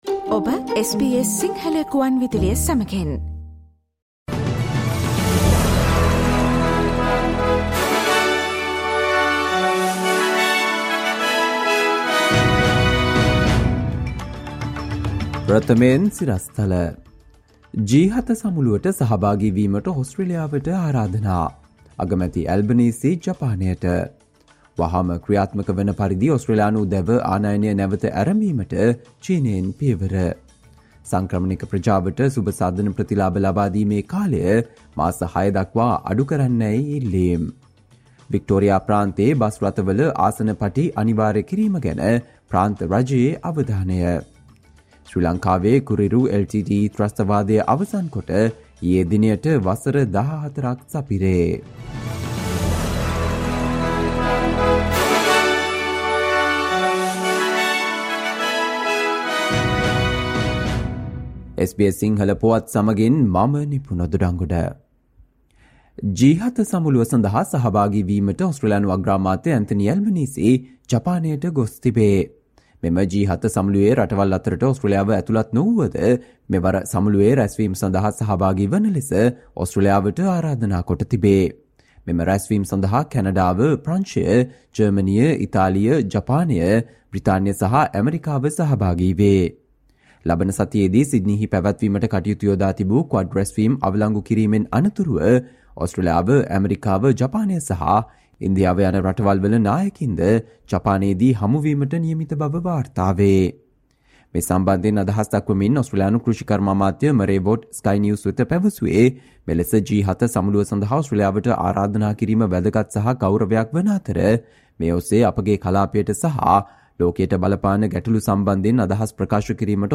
Listen to the latest news from Australia, across the globe, and the latest news from the sports world on SBS Sinhala radio news – Friday, 19 May 2023.